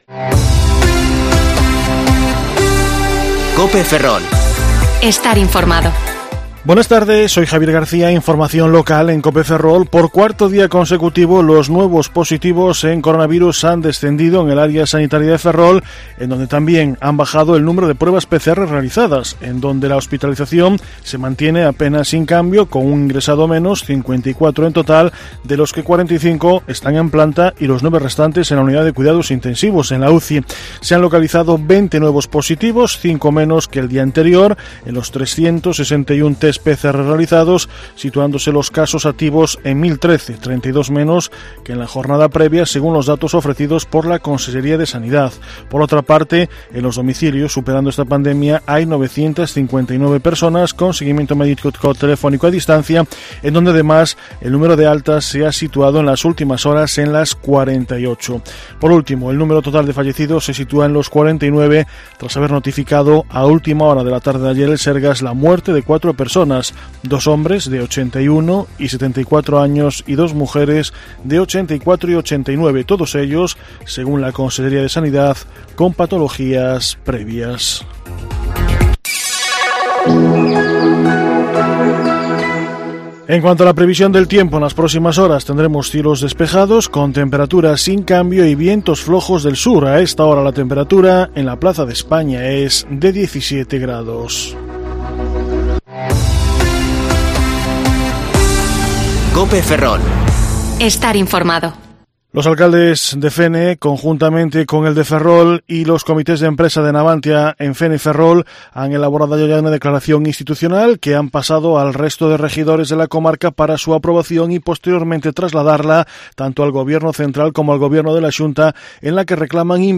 Informativo Mediodía COPE Ferrol 10/11/2020 (De 1420 a 14,30 horas)